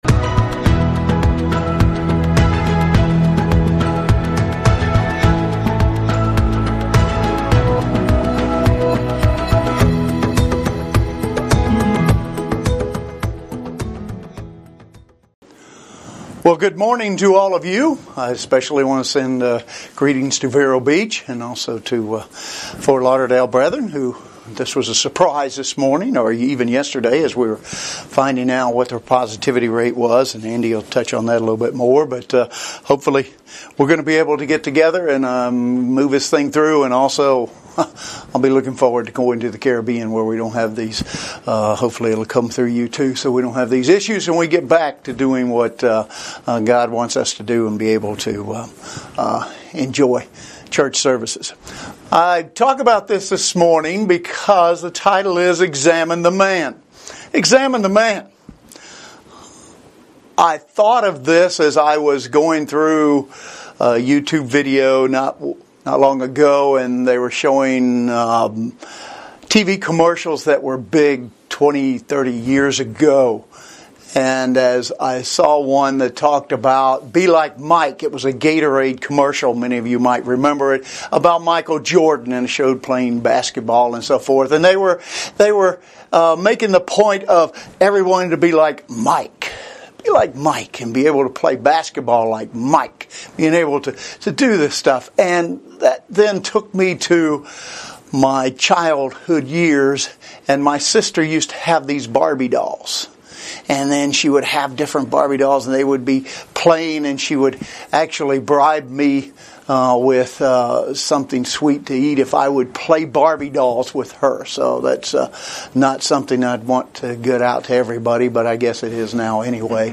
In this message, we will "examine the man."